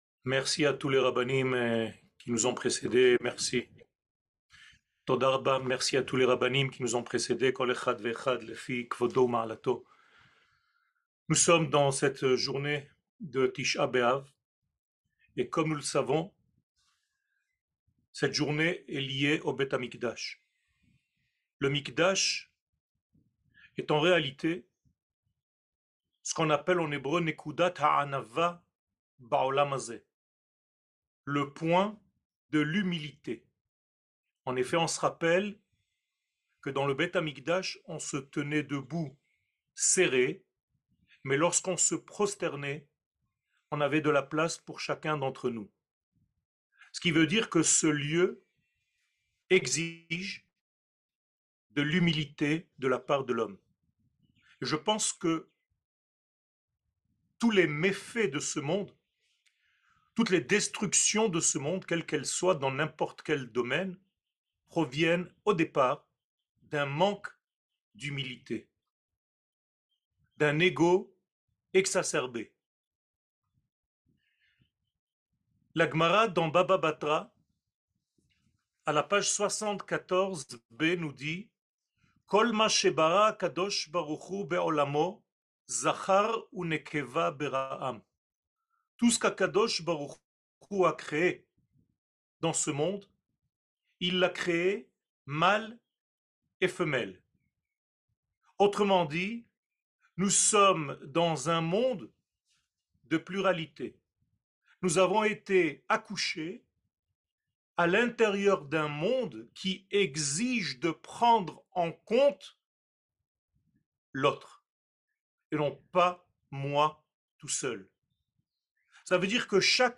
קטגוריה La relation ultime 00:39:03 La relation ultime שיעור מ 28 יולי 2023 39MIN הורדה בקובץ אודיו MP3